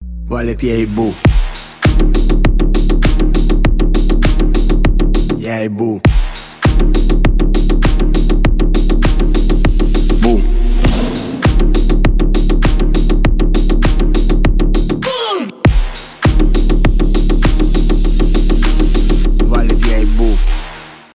басы , клубные